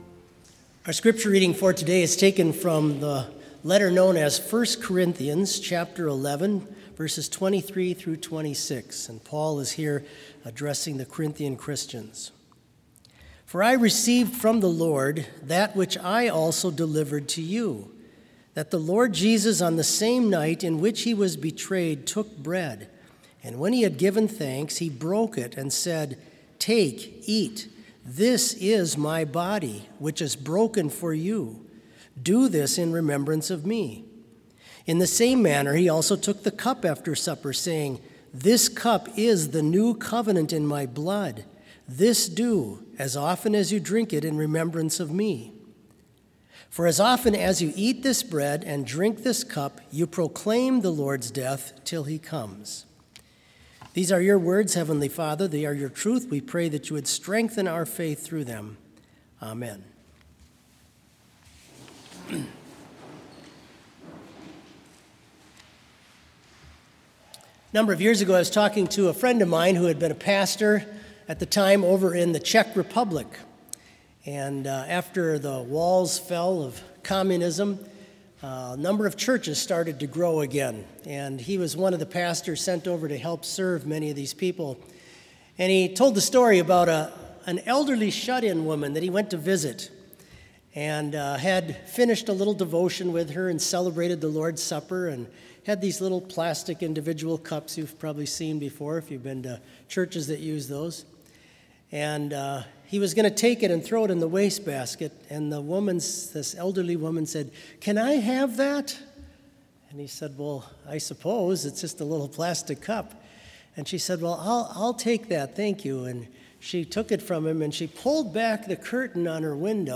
Complete service audio for Chapel - November 9, 2020